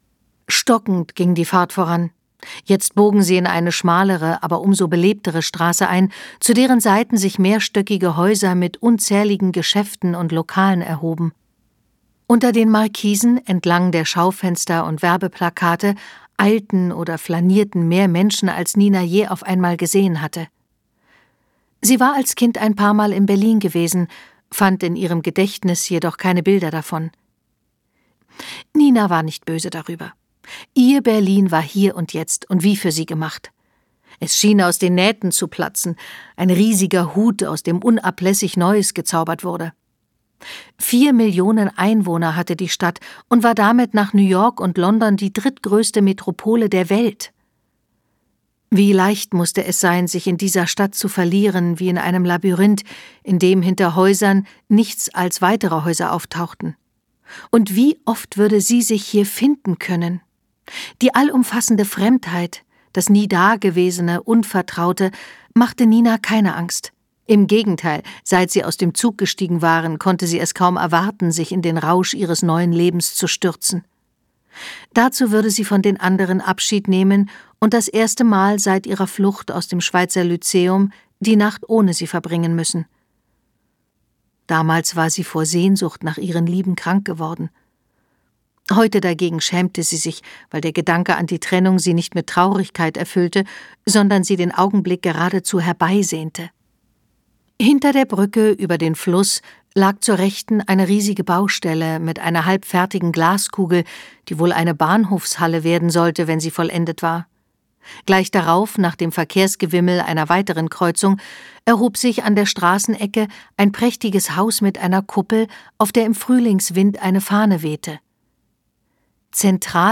Hörbuch: Die Wintergarten-Frauen.